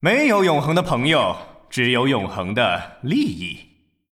大厅语音